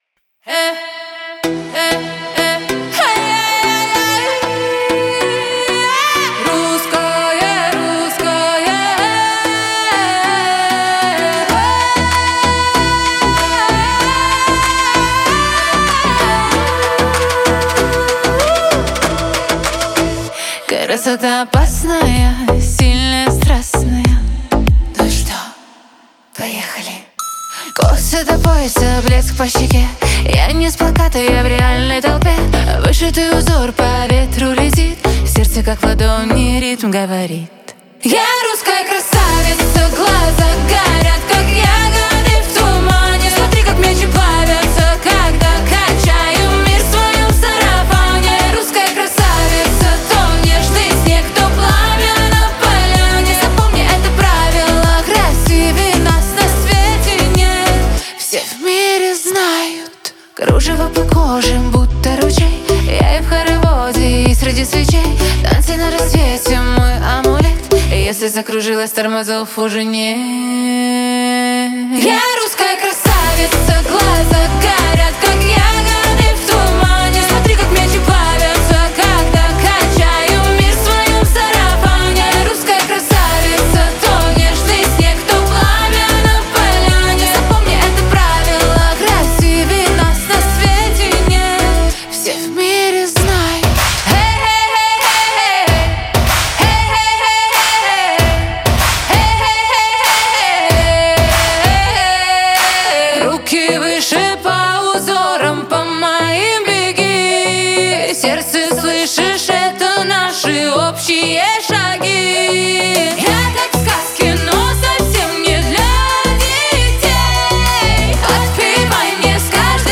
Дип хаус